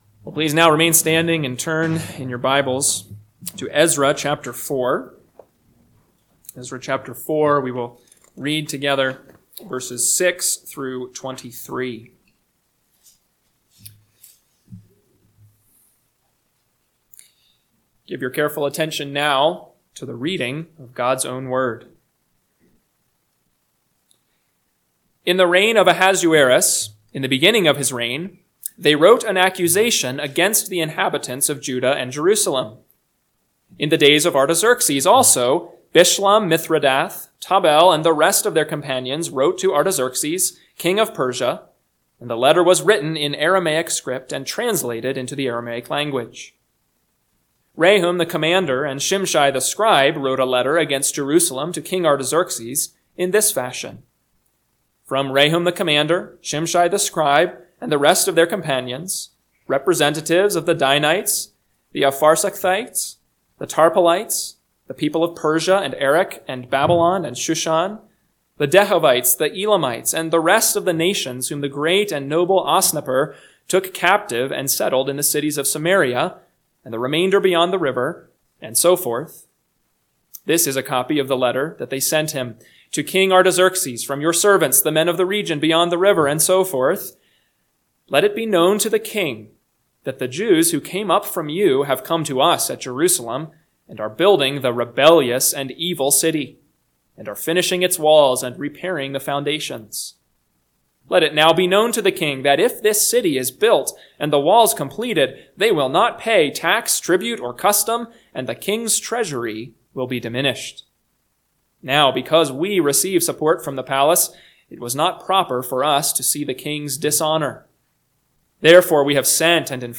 PM Sermon – 3/23/2025 – Ezra 4:6-23 – Northwoods Sermons